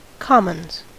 Uttal
Uttal US Okänd accent: IPA : /ˈkɑm.ənz/ IPA : /ˈkɒm.ənz/ Ordet hittades på dessa språk: engelska Ingen översättning hittades i den valda målspråket.